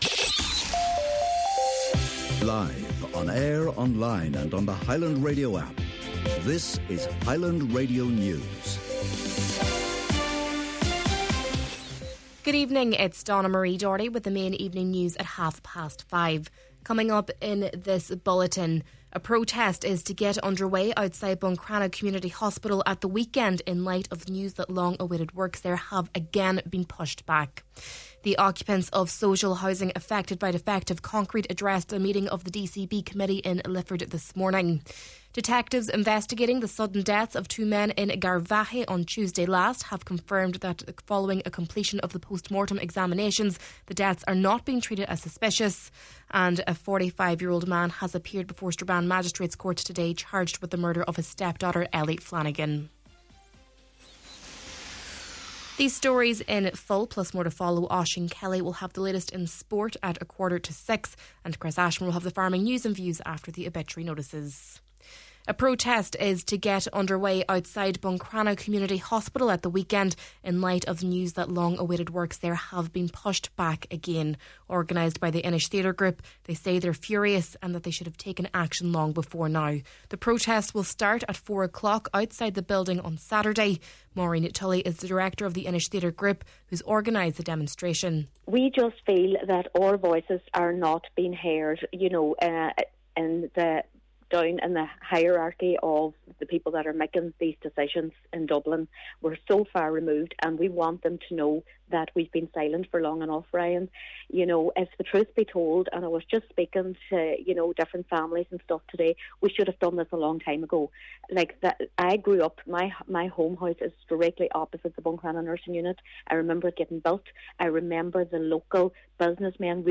Main Evening News, Sport, Farming News and Obituary Notices – Thursday, March 12th